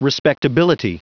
Prononciation du mot respectability en anglais (fichier audio)
Prononciation du mot : respectability